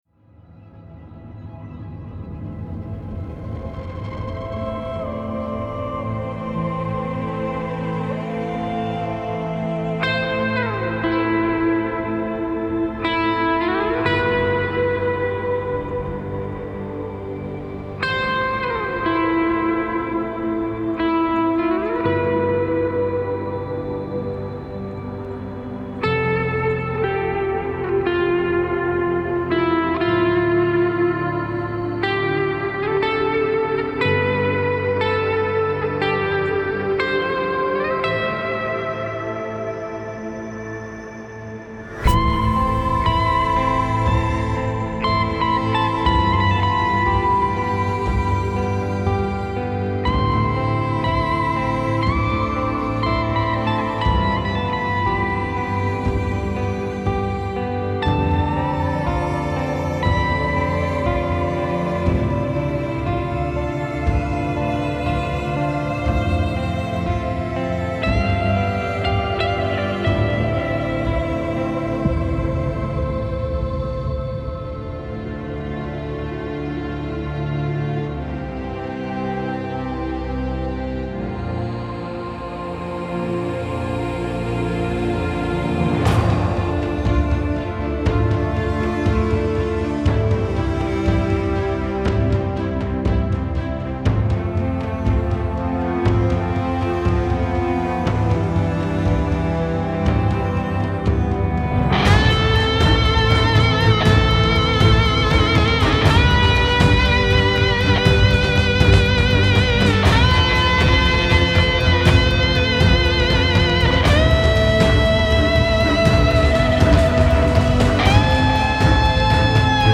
الهام‌بخش , امید‌بخش , گیتار الکترونیک , موسیقی بی کلام